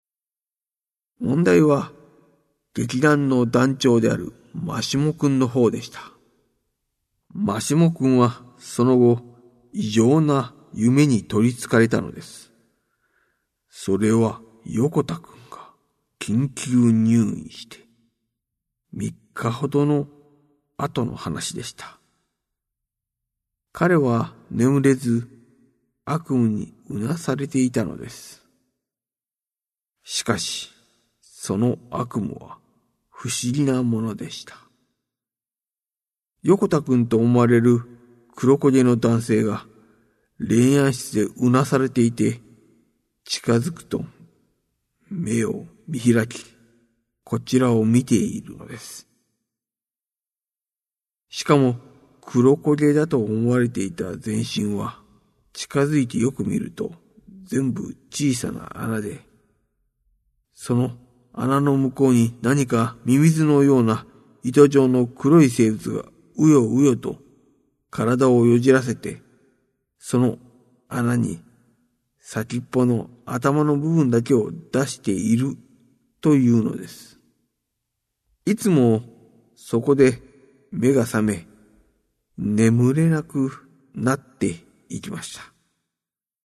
殺人事件や心理描写までを、まるで映像を見ているかのように語る新感覚の怪談。